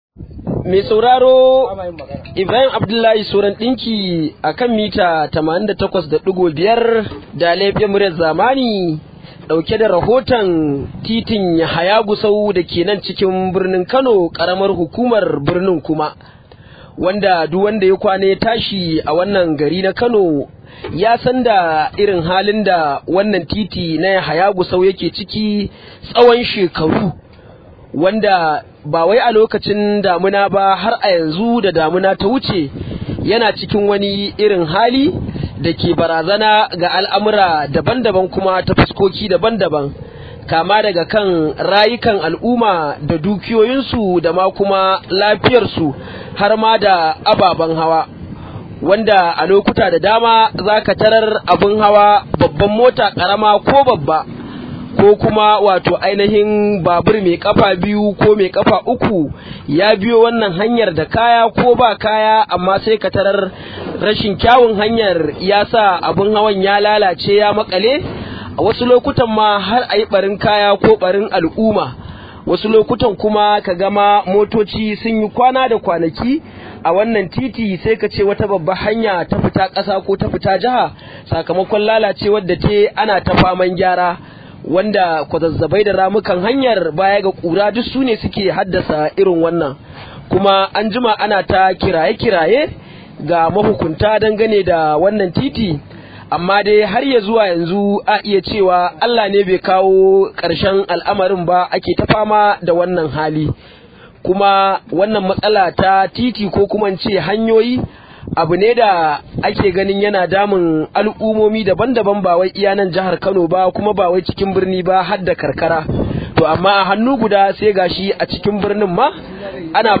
Rahoto: Al’umma na ci gaba da kokawa a kan rashin kyan Titin Yahaya Gusau
Wani direba da babbar motar sa ta fada wani rami tsawon kwana daya suna kokarin ciro motar, ya koka kan yadda lalacewar titin ya janyo lalacewar ababen hawa.